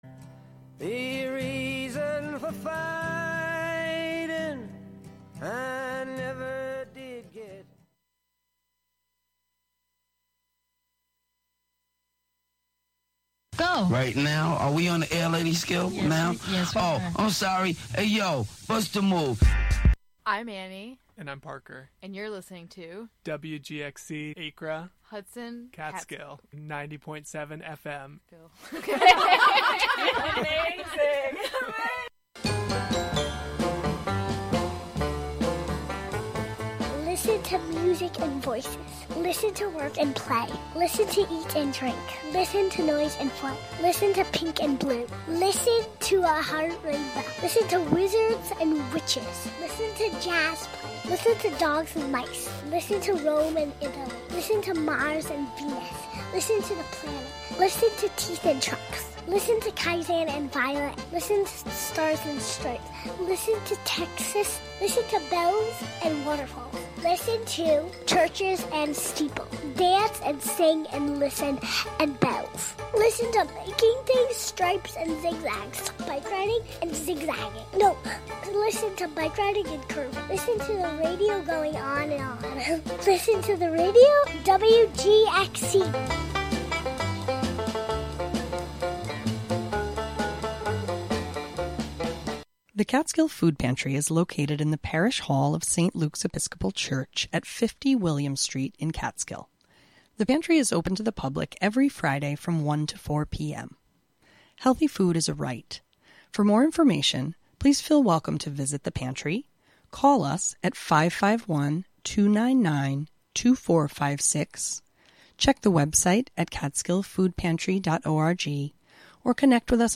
This hour is here to remind you that we are all the Grandmasters of our own lives. In this episode, it's blues blues blues and more blues.
There is also a reading from Richard Brautigan's Trout Fishing in America book.